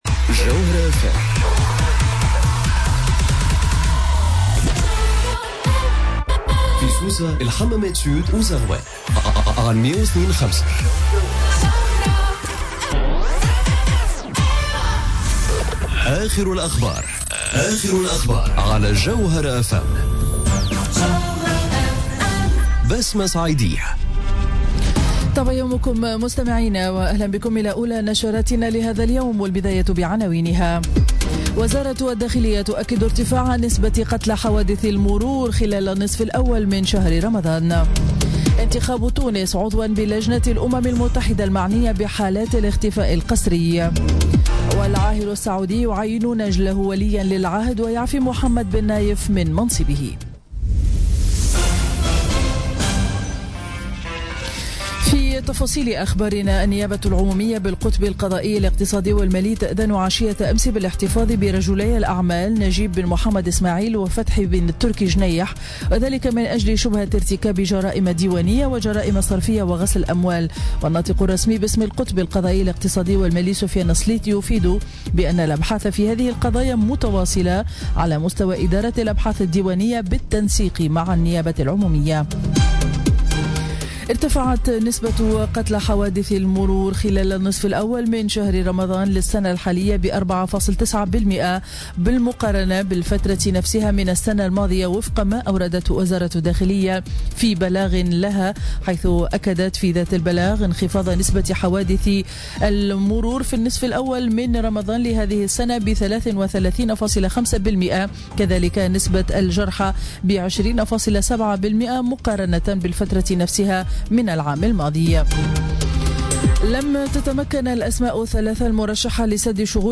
نشرة أخبار السابعة صباحا ليوم الإربعاء 21 جوان 2017